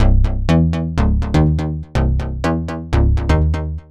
The Bounce_123_C.wav